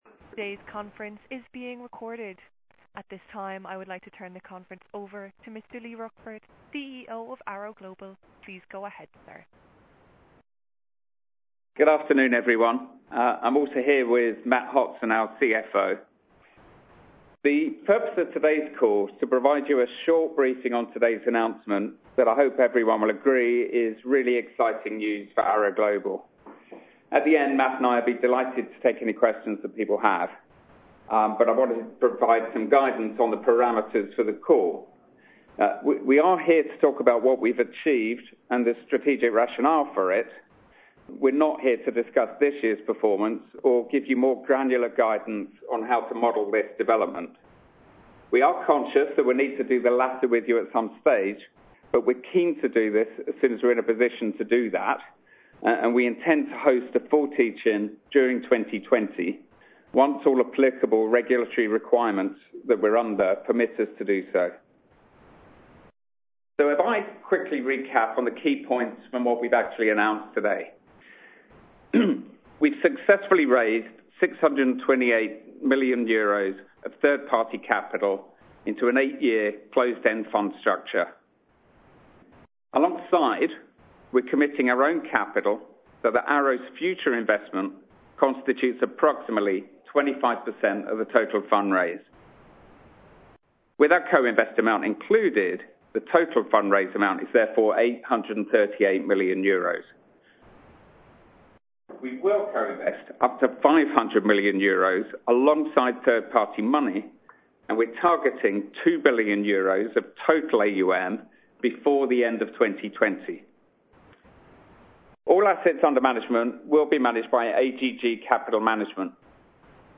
Arrow Global Group plc announces a €838.0 million fund raising for inaugural pan-european npl fund announcement Investor call